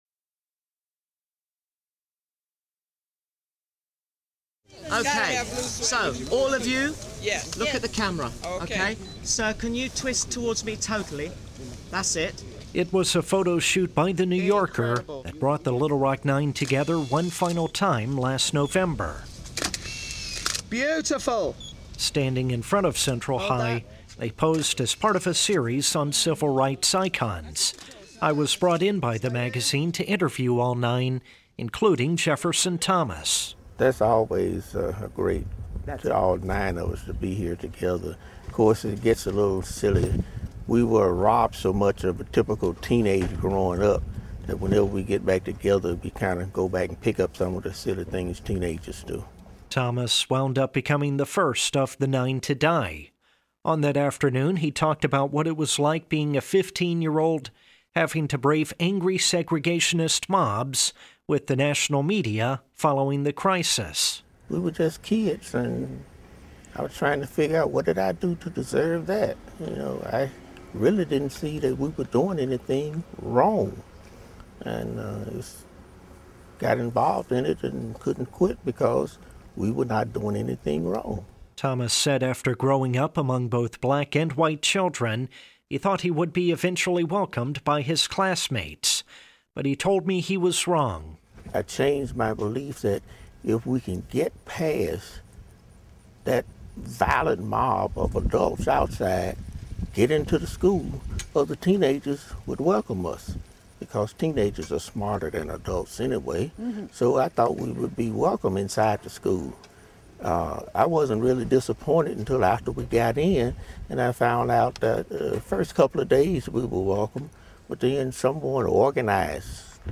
One of the Little Rock Nine will be remembered tomorrow at a memorial service. We hear a report about what Jefferson Thomas said the last time all of the nine Little Rock students were together.